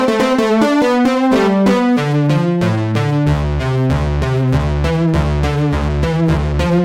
软合成器
标签： 合成器